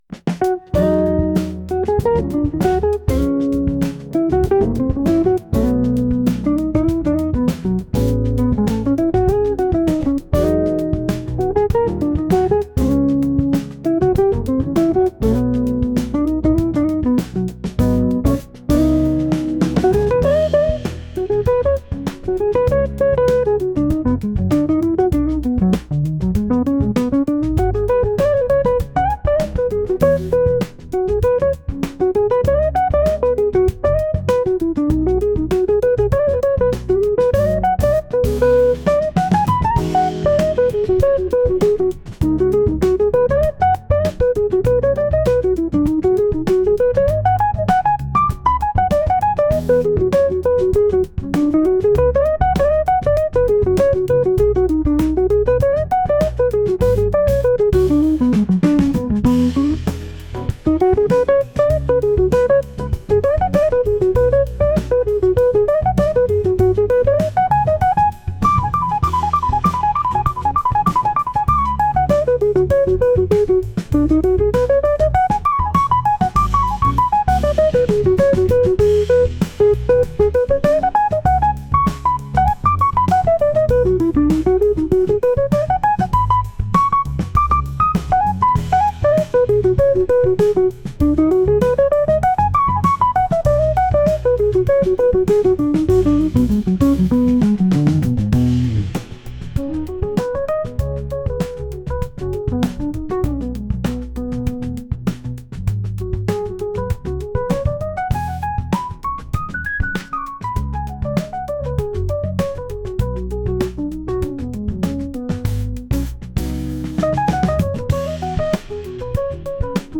こちらもぺそぺそとゆったりとしたエレキギター曲です。